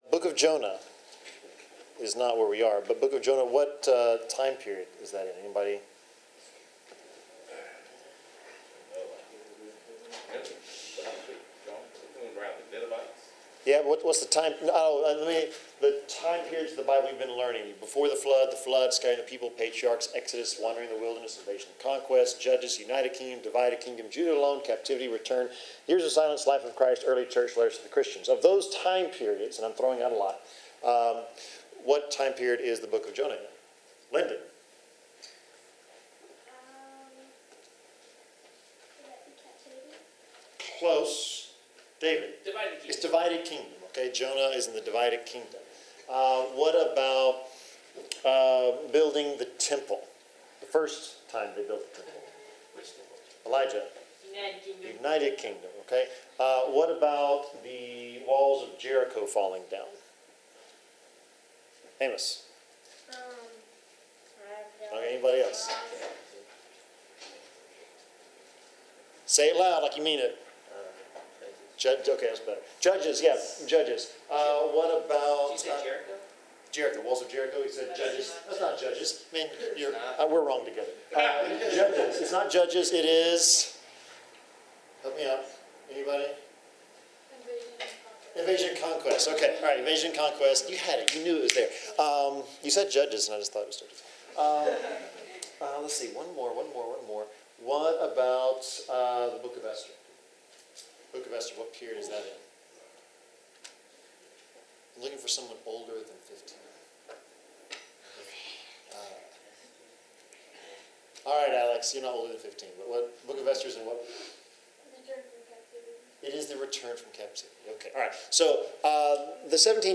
Bible class: Deuteronomy 15-16
Service Type: Bible Class